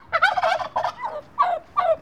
دانلود صدای بوقلمون برای کودکان از ساعد نیوز با لینک مستقیم و کیفیت بالا
جلوه های صوتی
برچسب: دانلود آهنگ های افکت صوتی انسان و موجودات زنده